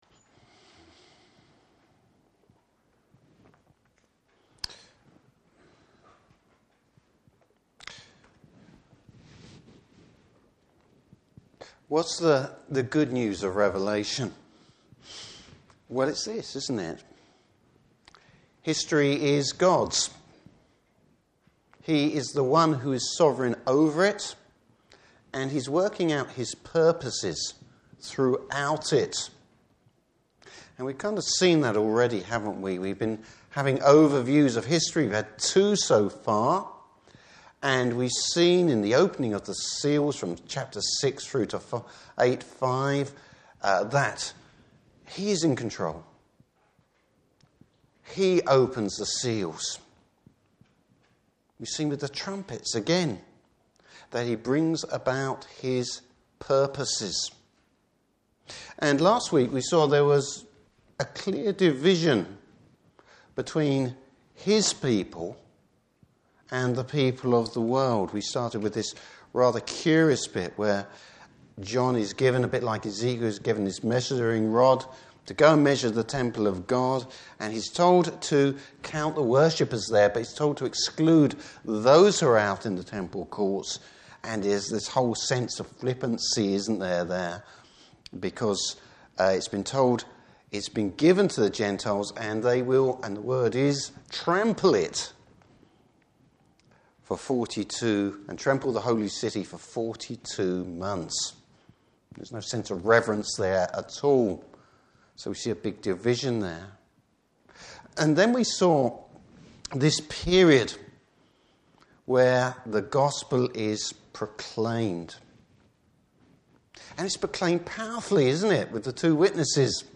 Service Type: Evening Service Bible Text: Revelation 12:1-13:1a.